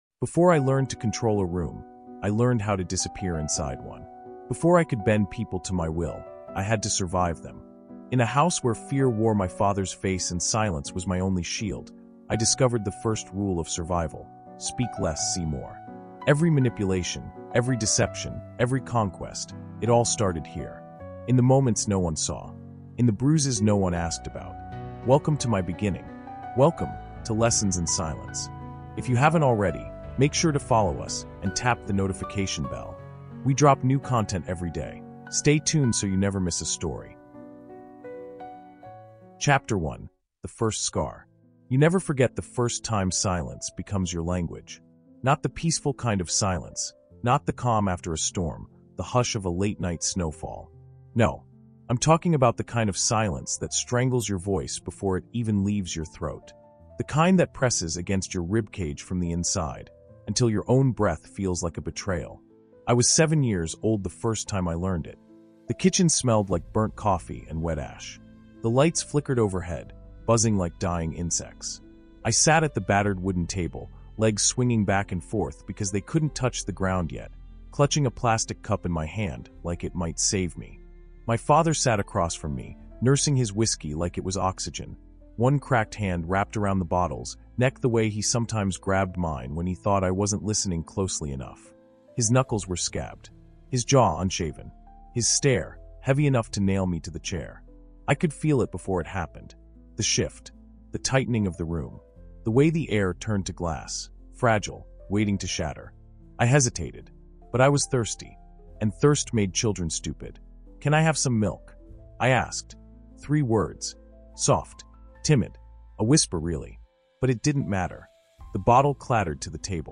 Inside the Mind of a Master Manipulator | Lessons in Silence | Audiobook